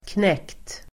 Ladda ner uttalet
Uttal: [knek:t]